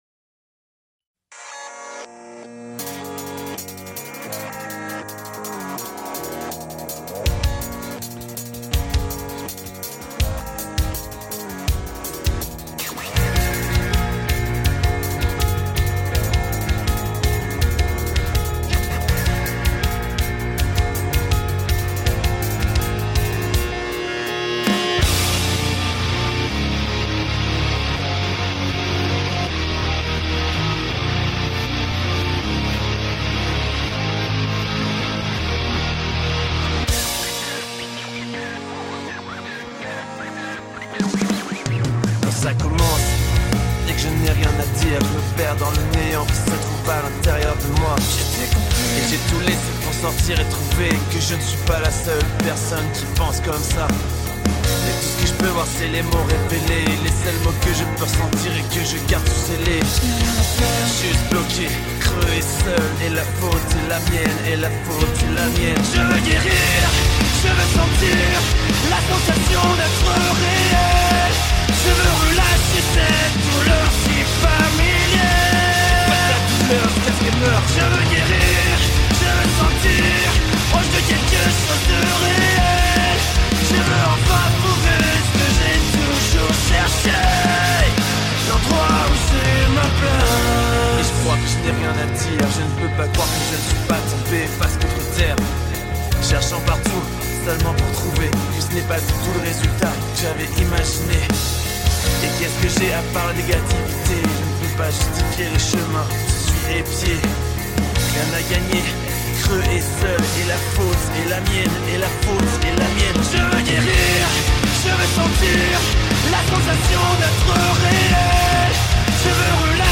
drum cover
french singing cover
🥁 Electronic drum kit
Drummer 🥁